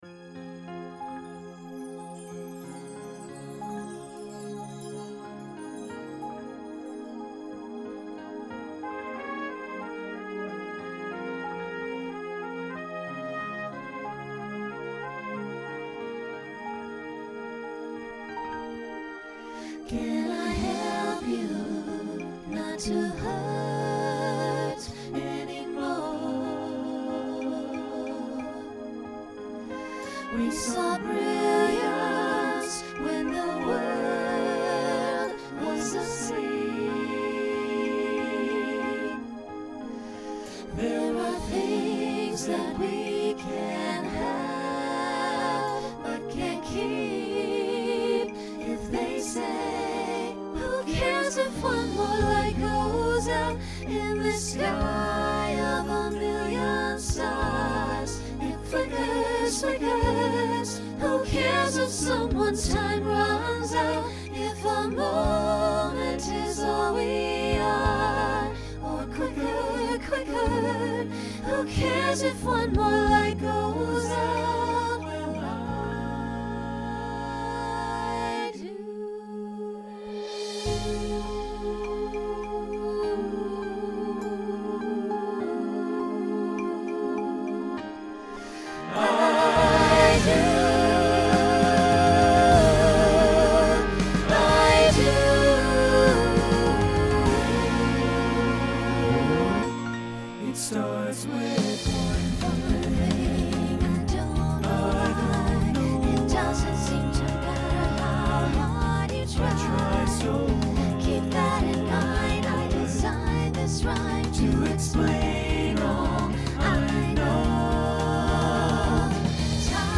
Genre Rock
Ballad Voicing SATB